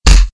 Kick2